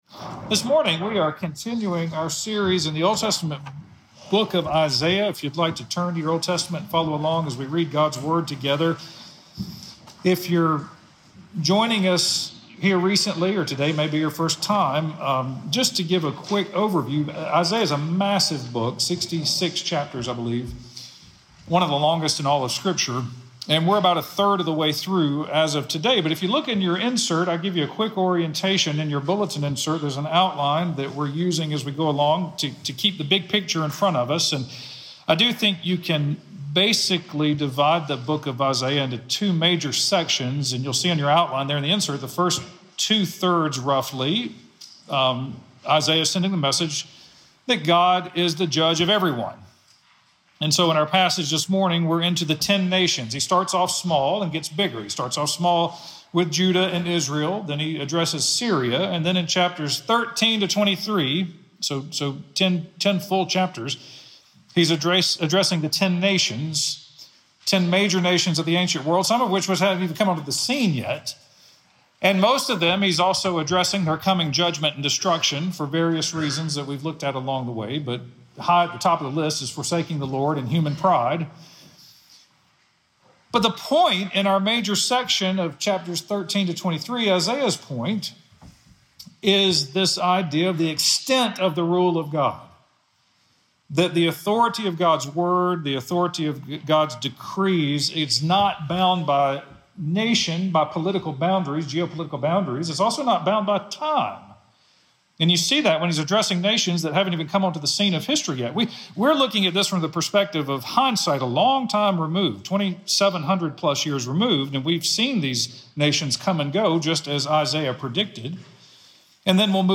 The Lord of the Nations Sermon